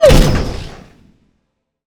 sci-fi_weapon_rifle_laser_shot_01.wav